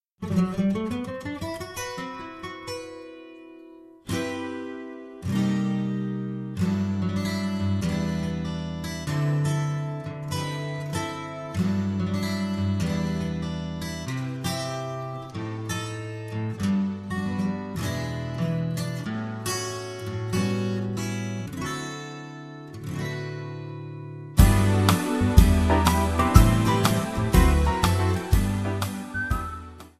Backing track Karaoke
Pop, Musical/Film/TV, 1990s